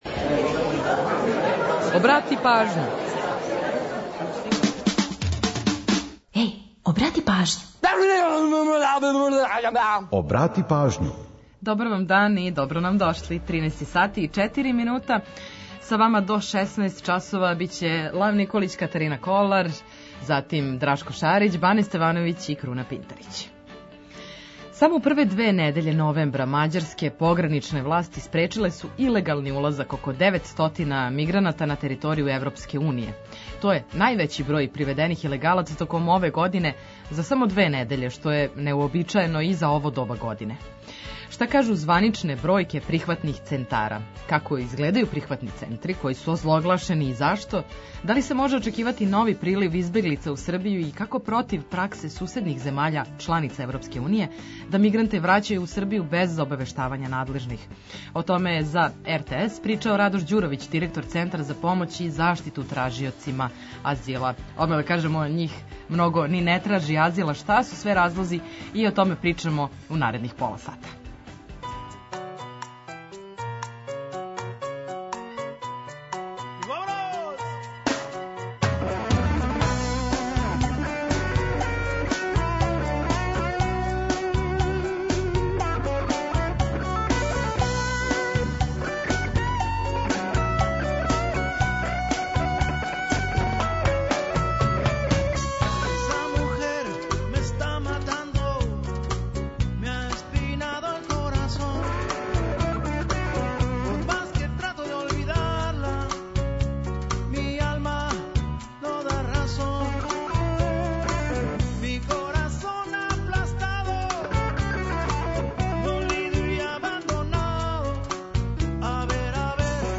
Ту је и пола сата музике само из Србије и региона, новитети са топ листа, приче о настанку и занимљивом „животу” разних песама и наш репортер са актуелним градским информацијама.